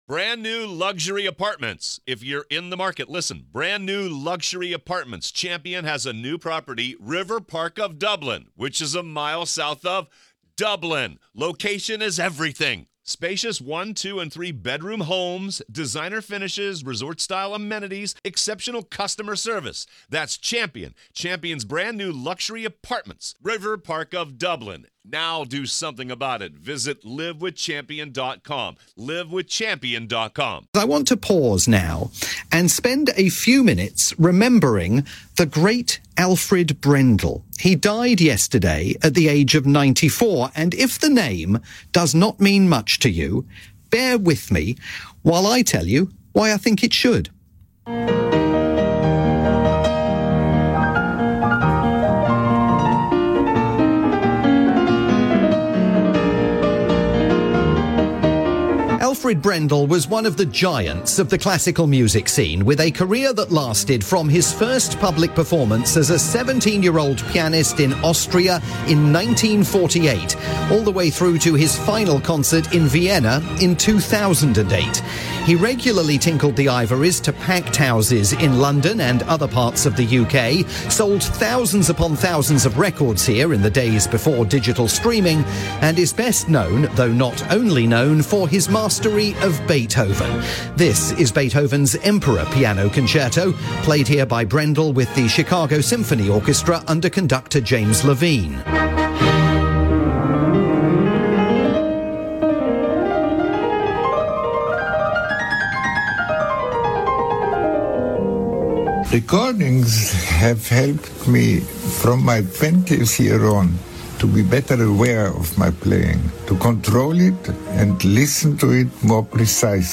With the thoughts of Classic FM legend, and Beethoven scholar John Suchet.